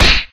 Blow5.ogg